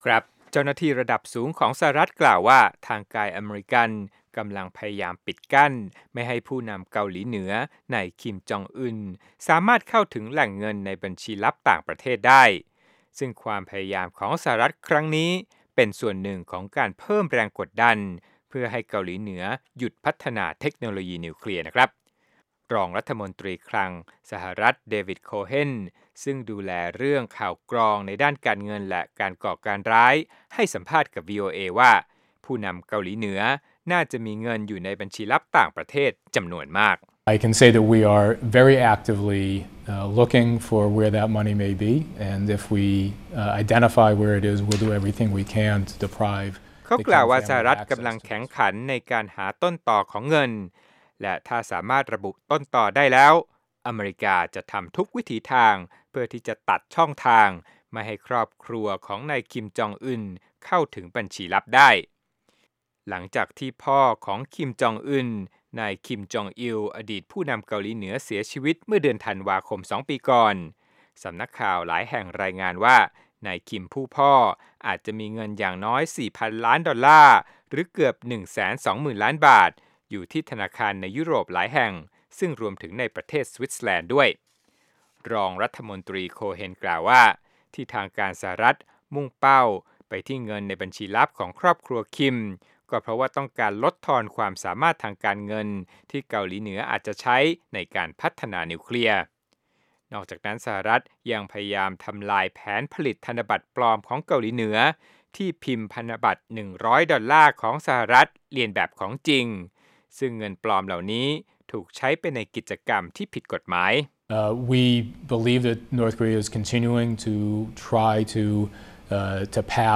สัมภาษณ์พิเศษ: รองรัฐมนตรีคลังสหรัฐกล่าวว่าจะพยายามปิดกั้นไม่ให้ Kim Jong Un เข้าถึงเงินในบัญชีลับต่